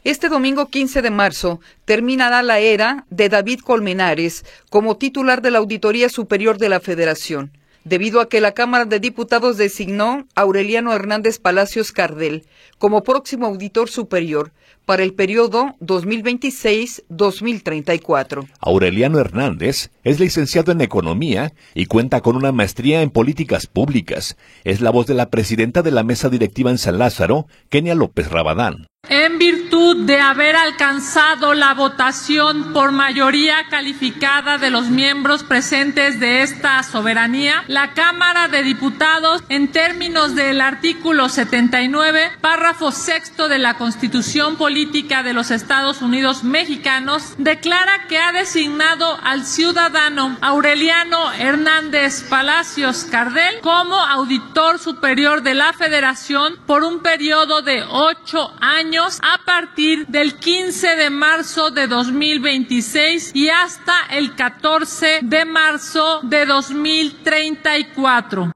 Es la voz de la presidenta de la Mesa Directiva en San Lázaro, Kenia López Rabadán.